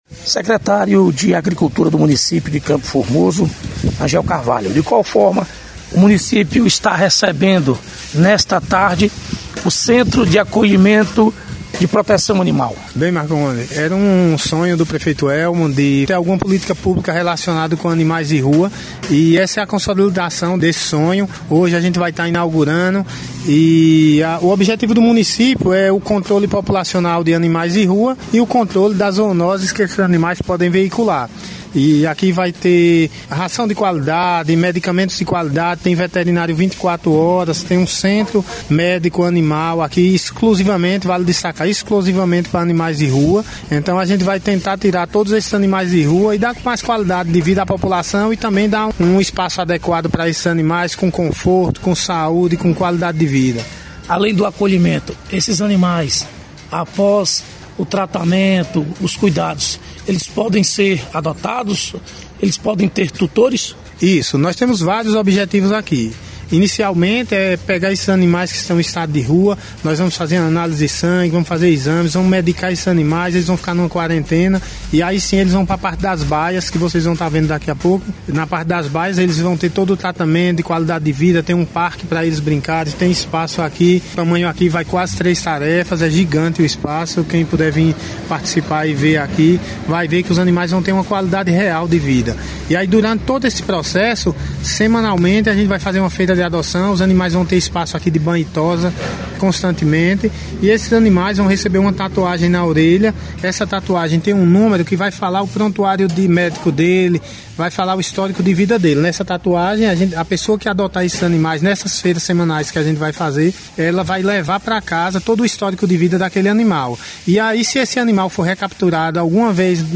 Secretário de agricultura de CFormoso, Rangel Carvalho – Centro de acolhimento de animais em Campo Formoso